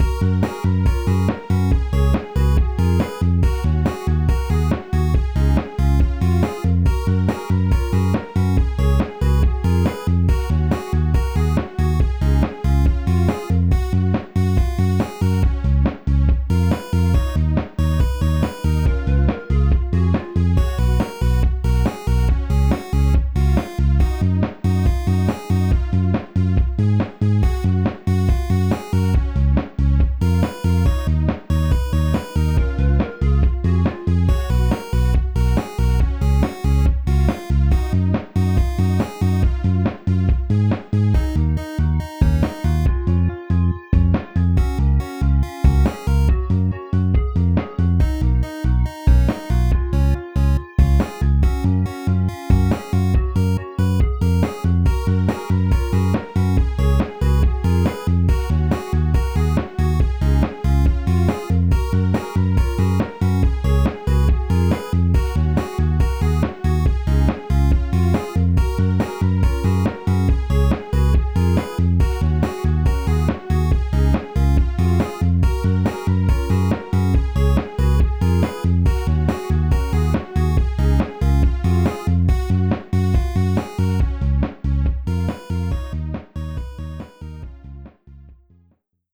BGM
インストゥルメンタルエレクトロニカショート明るい